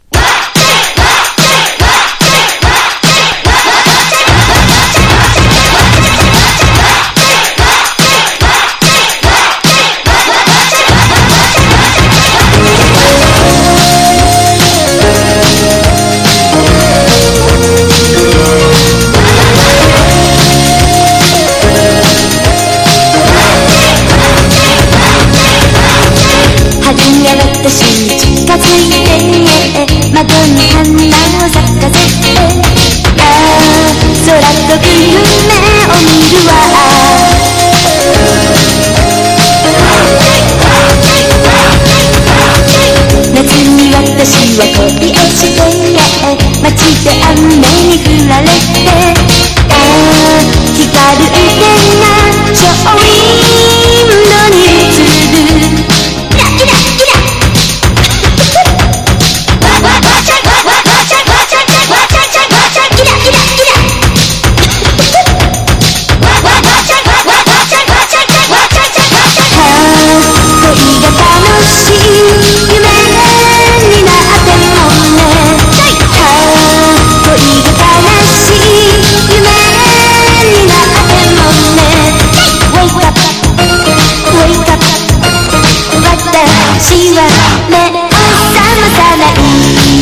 当時まだ珍しかったサンプリングを使用した脱力テクノ歌謡音頭！
POP